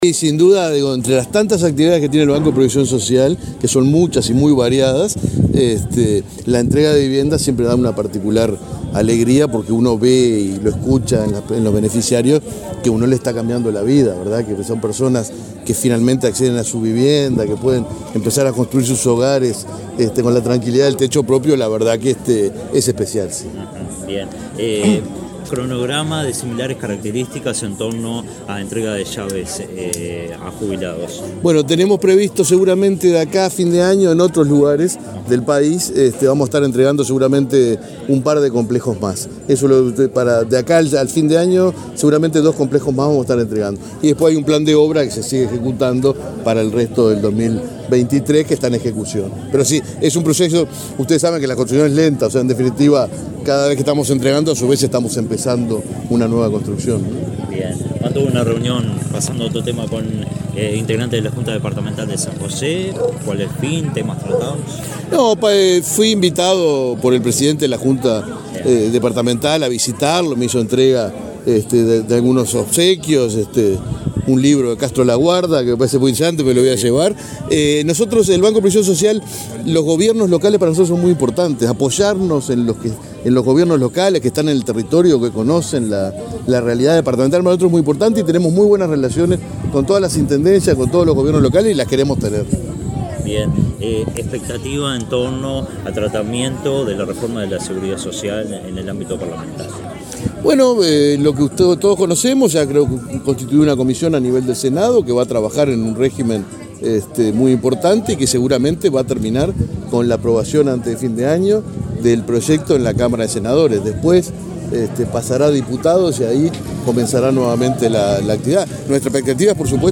Declaraciones del presidente del BPS, Alfredo Cabrera
Declaraciones del presidente del BPS, Alfredo Cabrera 04/11/2022 Compartir Facebook X Copiar enlace WhatsApp LinkedIn Este viernes 4, el presidente del Banco de Previsión Social (BPS), Alfredo Cabrera, participó en la inauguración de viviendas para pasivos en el departamento de San José. Luego dialogó con la prensa.